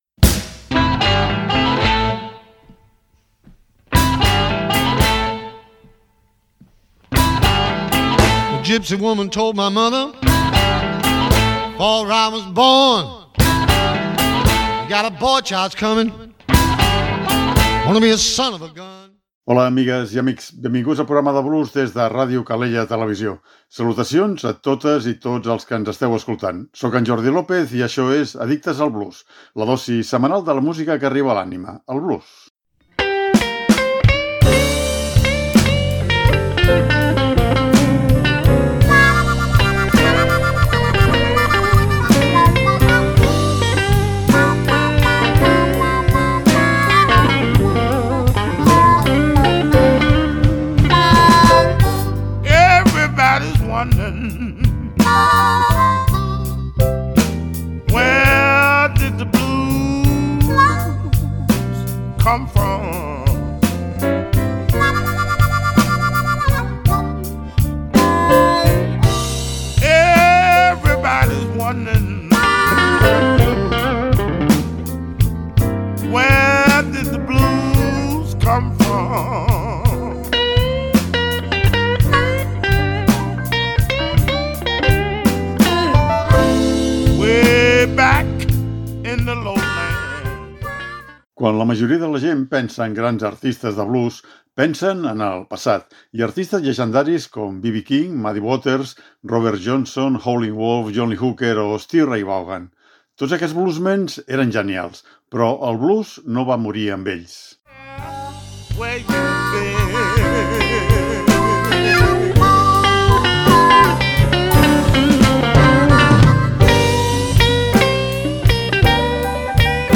Aquí hi ha alguns bluesmen contemporanis que mantenen viva la flama per a la pròxima generació. Nou bluesmen poc coneguts però, al cap i a la fi, intèrprets del gran BLUES.